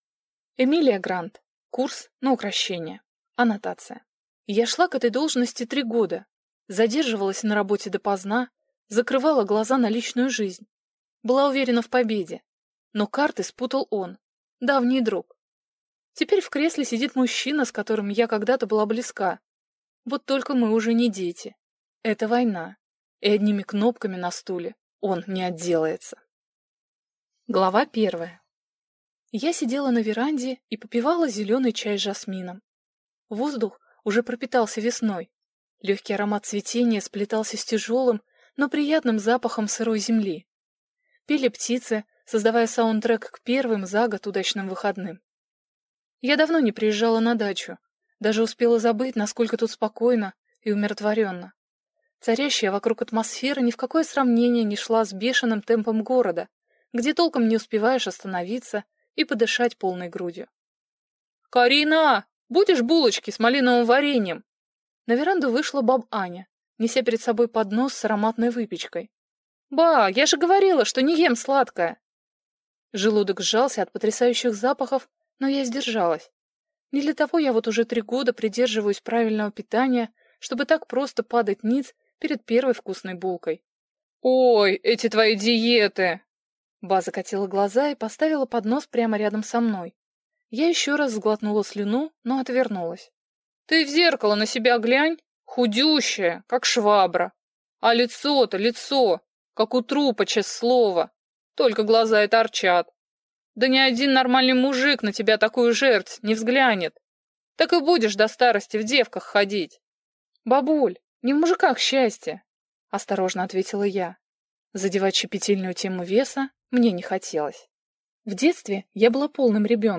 Аудиокнига Курс на укрощение | Библиотека аудиокниг
Прослушать и бесплатно скачать фрагмент аудиокниги